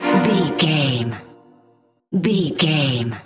Aeolian/Minor
Fast
tension
ominous
eerie
strings